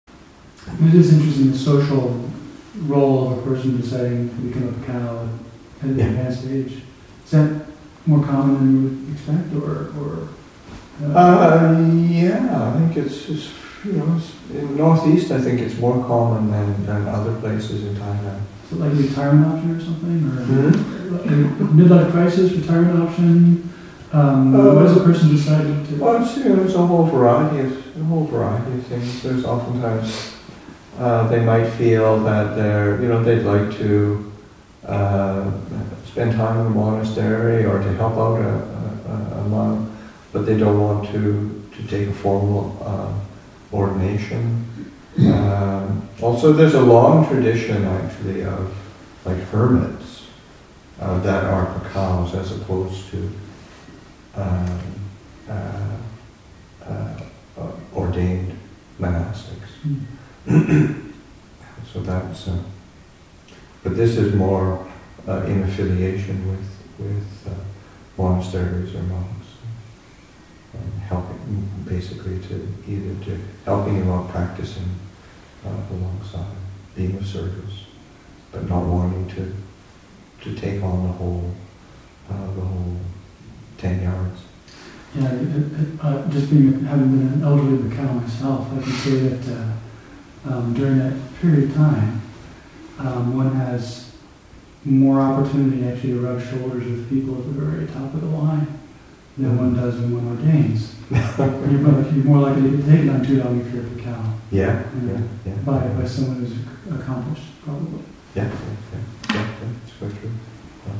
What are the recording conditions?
Our Roots in the Thai Forest Tradition, Session 49 – Mar. 13, 2014